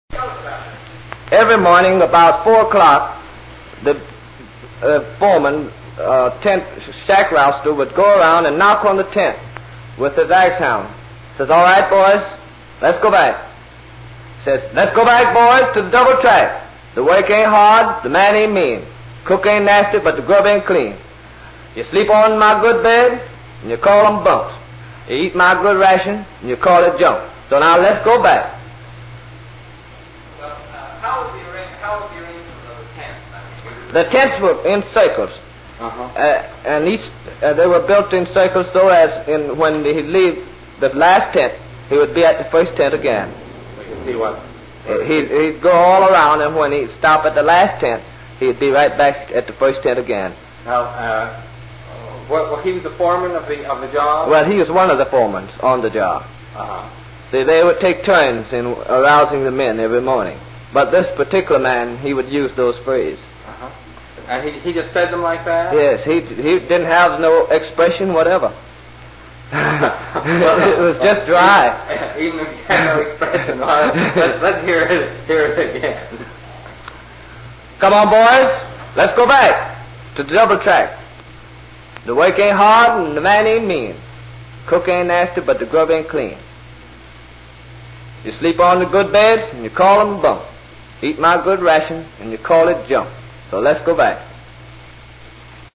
Field hollers